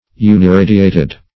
uniradiated - definition of uniradiated - synonyms, pronunciation, spelling from Free Dictionary
Uniradiated \U`ni*ra"di*a`ted\, a.